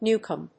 /ˈnukʌm(米国英語), ˈnu:kʌm(英国英語)/